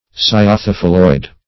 Cyathophylloid \Cy`a*tho*phyl"loid\ (s?`?-th?-f?l"loid), a. [NL.
cyathophylloid.mp3